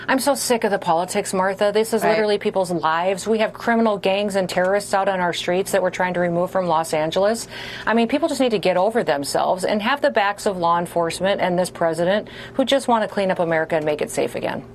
Afterward, Noem appeared on Fox News with Martha Maccallam.